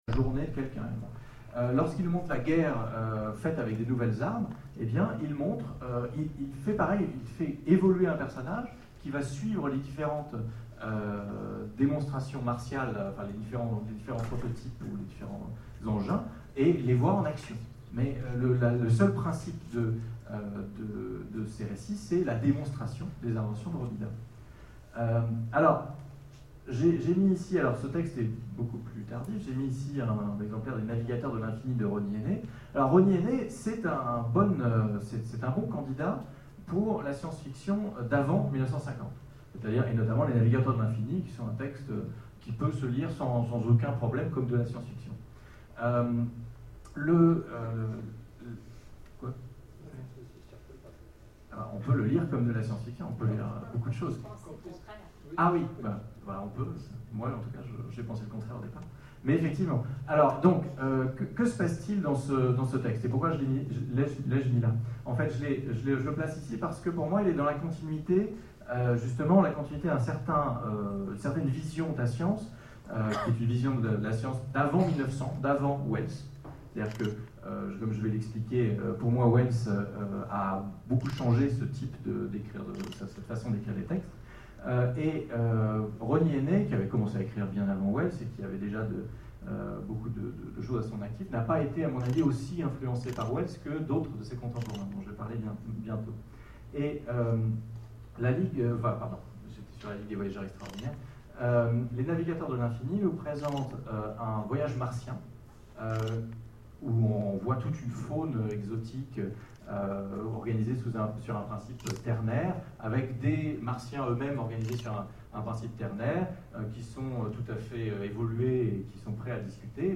9ème Rencontres de l'Imaginaire de Sèvres : Conférence La SF française